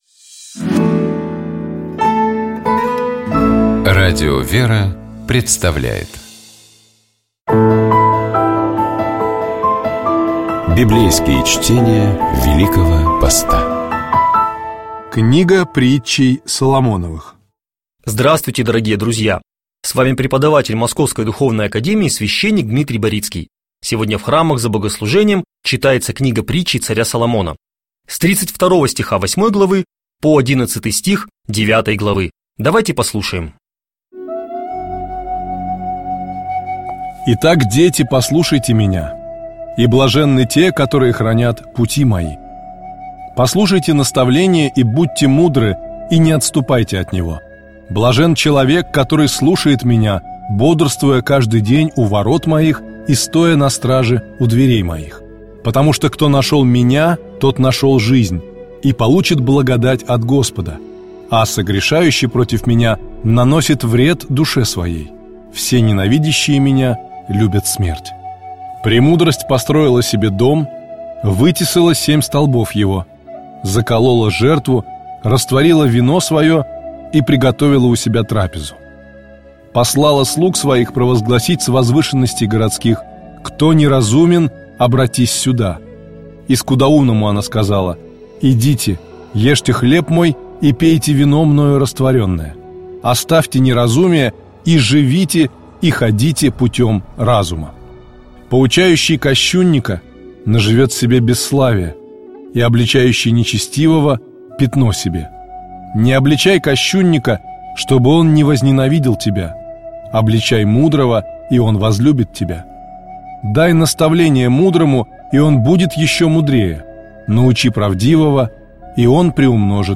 Библейские чтения
епископ Феоктист ИгумновЧитает и комментирует епископ Переславский и Угличский Феоктист